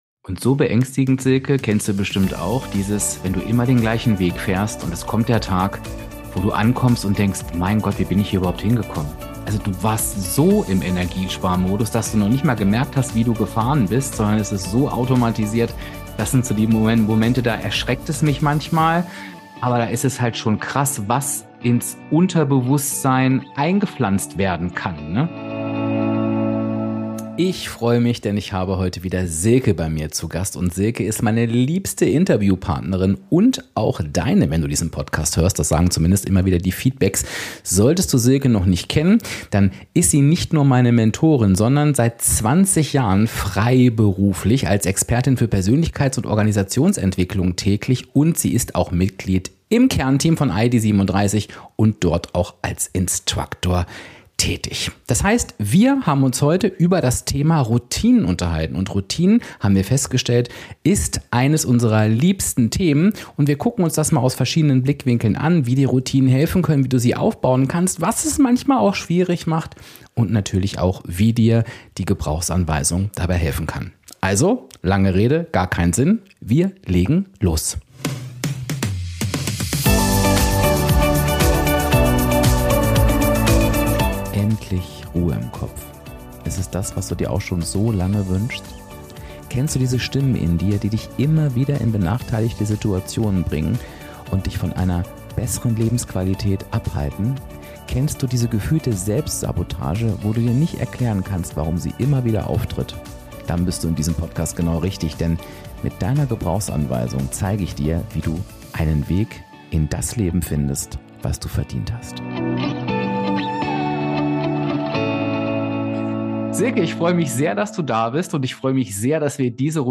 So helfen dir Routinen (Interview